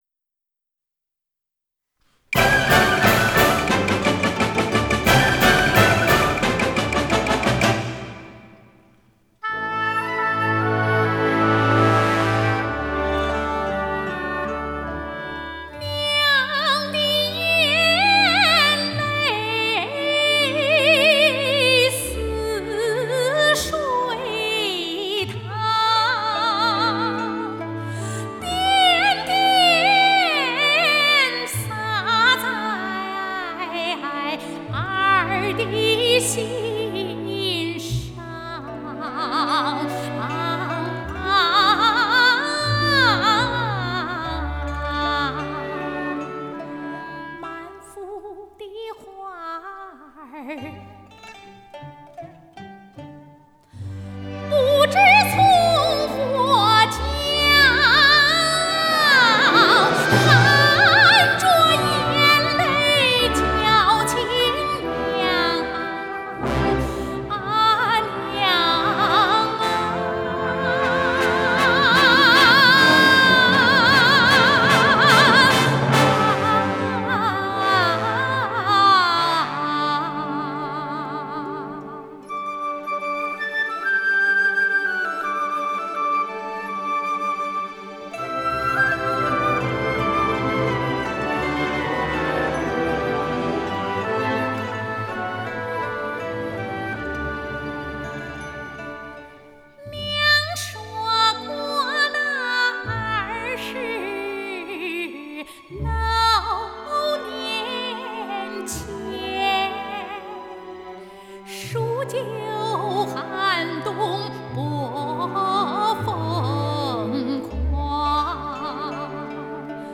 Жанр: Chinese pop ∕ Chinese folk